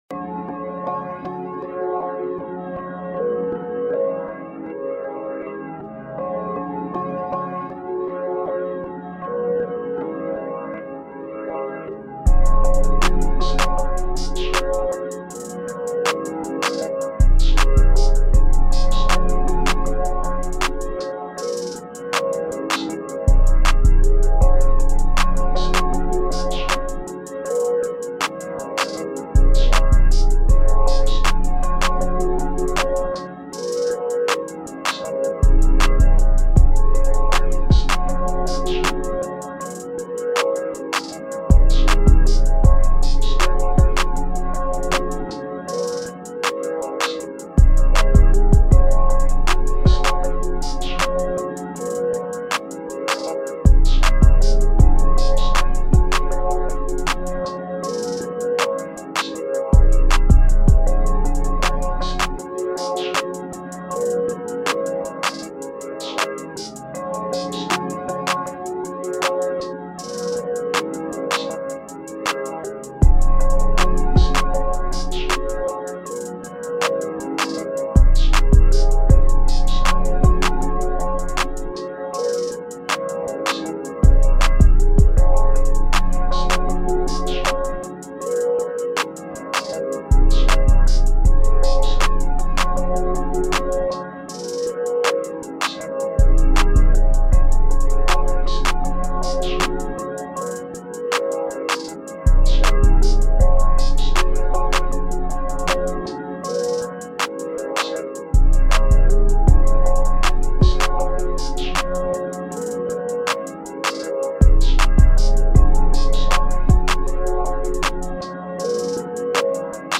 2022 in Hip-Hop Instrumentals